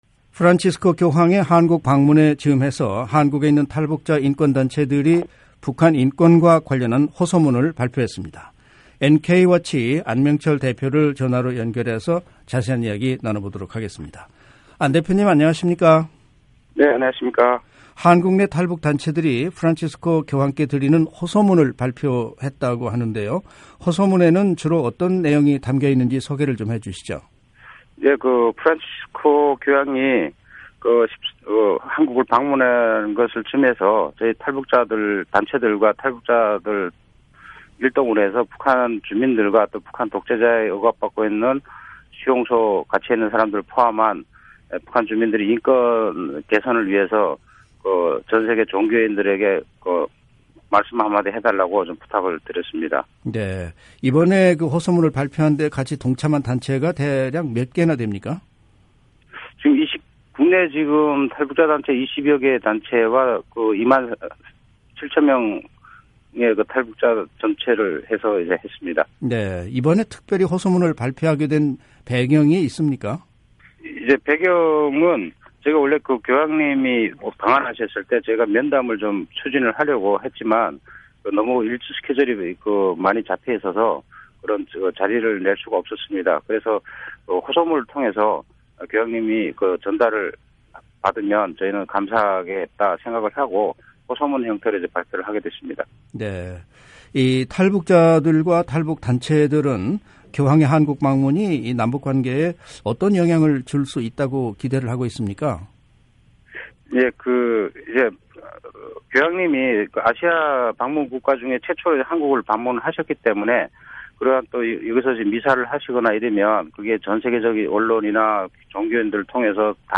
[인터뷰 오디오 듣기] 교황께 드리는 호소문 “북한인권 위해 기도해 주시길”